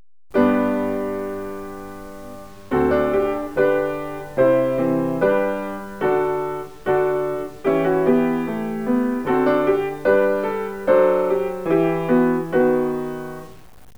Now the same chorale will be played backwards